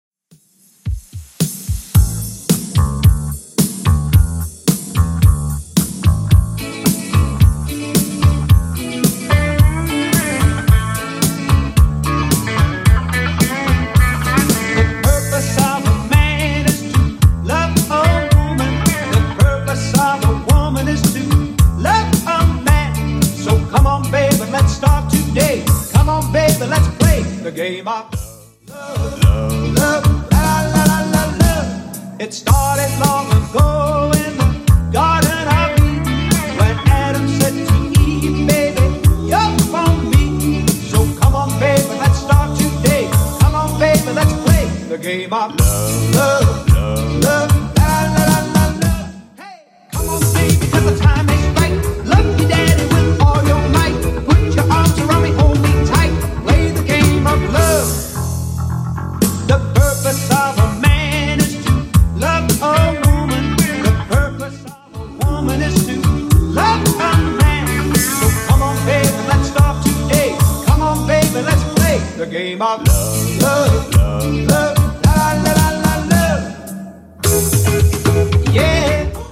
Genre: 70's
BPM: 110